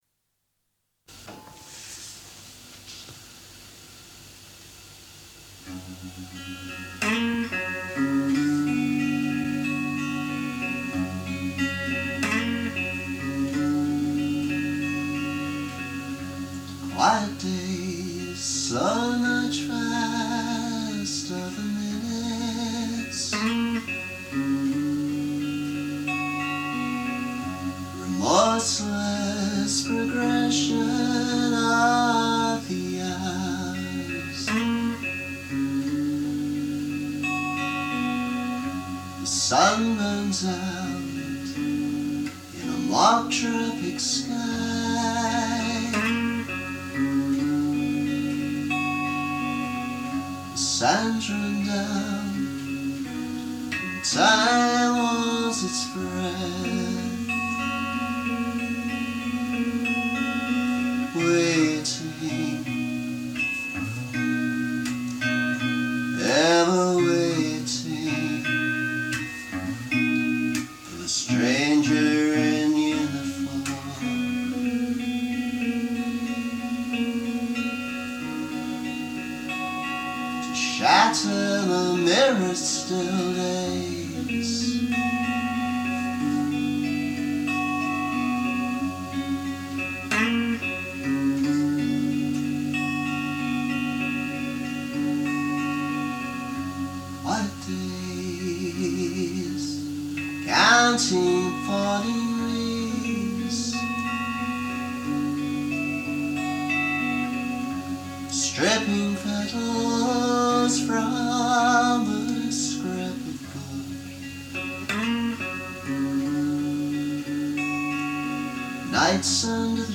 Antique electric version: